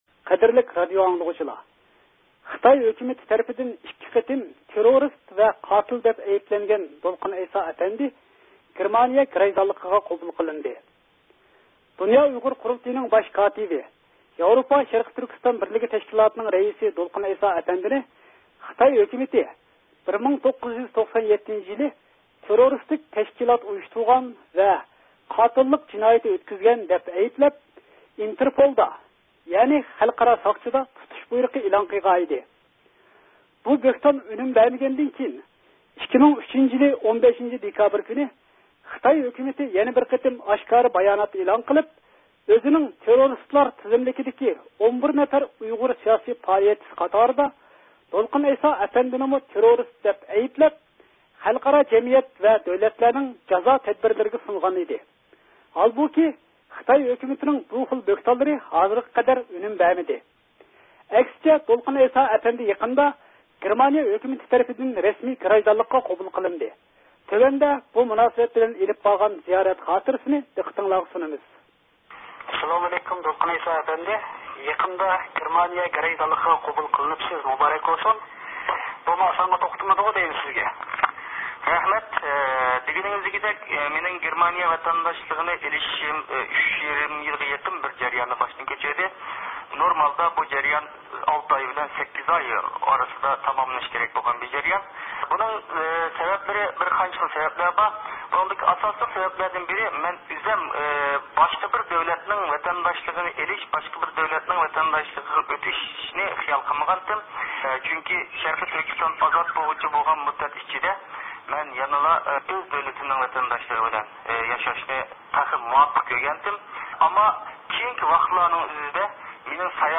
دۇنيا ئۇيغۇر قۇرۇلتىيىنىڭ باش كاتىپى دولقۇن ئەيسا ئەپەندى بىلەن سۆھبەتلەشتى.